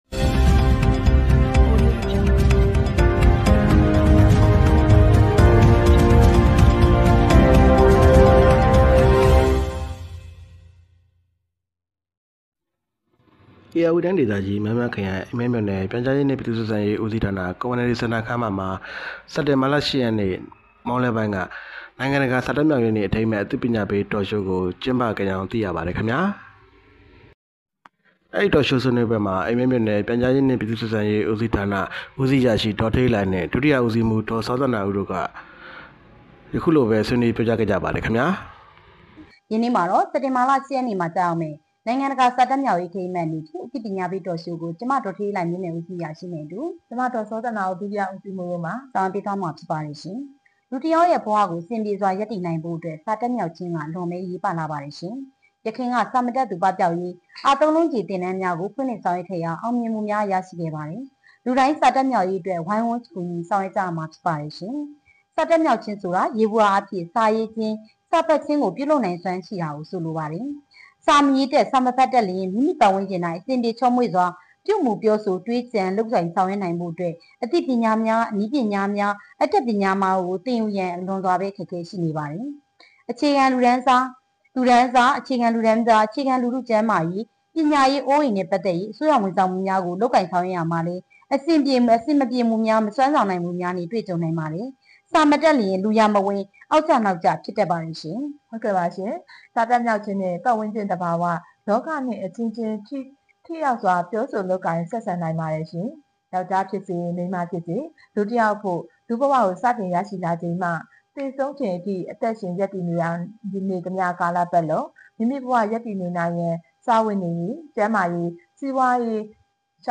အိမ်မဲမြို့နယ် Community Centre ခန်းမတွင် နိုင်ငံတကာစာတတ်မြောက်ရေးနေ့အထိမ်း...